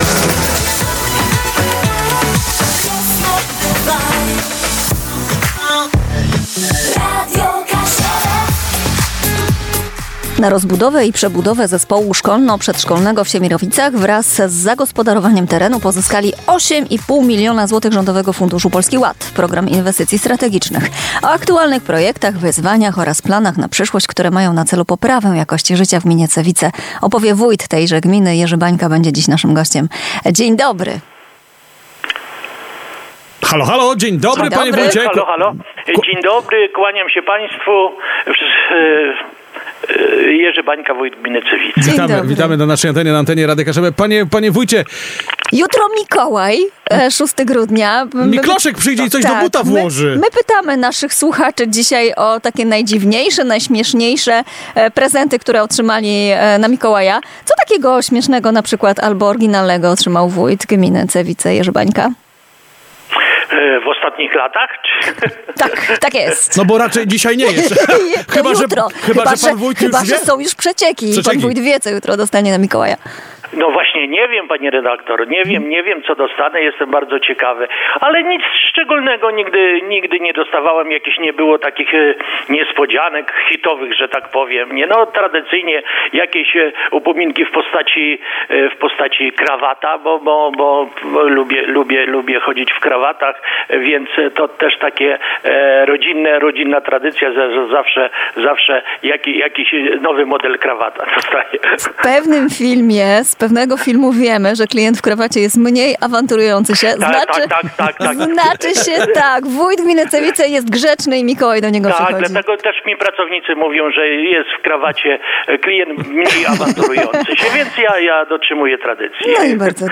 O aktualnych projektach, wyzwaniach oraz planach na przyszłość, które mają na celu poprawę jakości życia w gminie Cewice opowie wójt gminy – Jerzy Bańka.
rozmowa_Jerzy-Banka.mp3